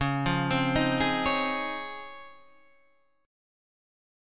C# Major 9th